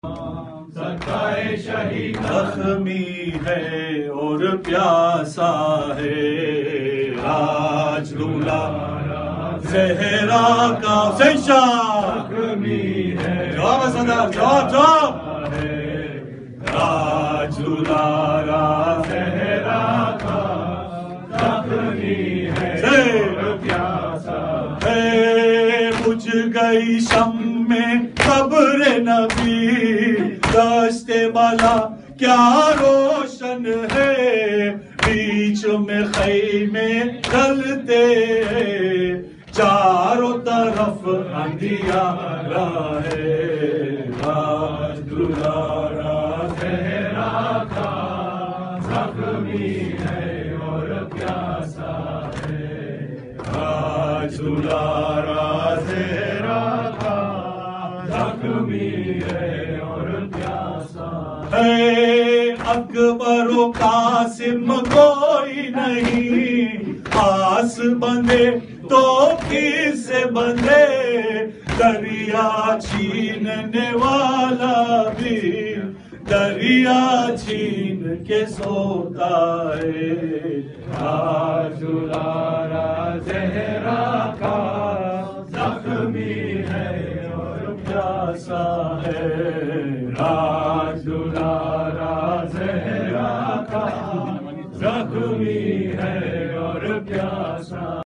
Submit lyrics, translations, corrections, or audio for this Nawha.